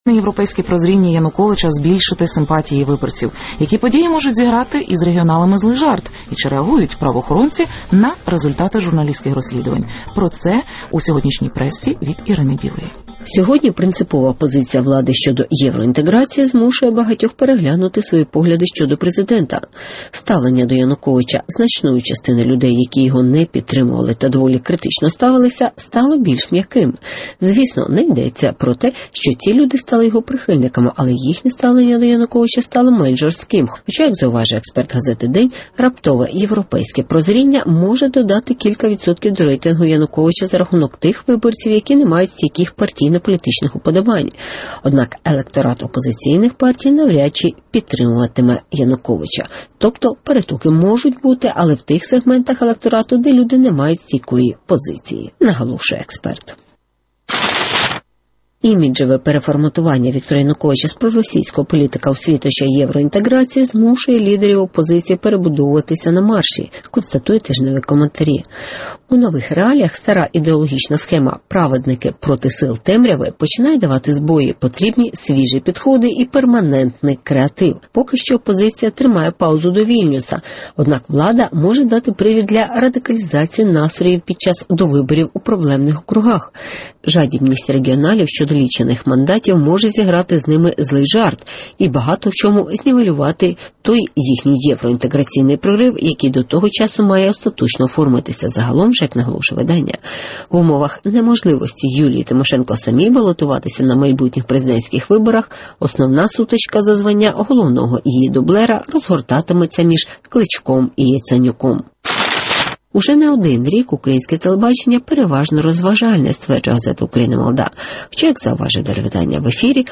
Жадібність регіоналів до мандатів зіграє з ними злий жарт? (Огляд преси)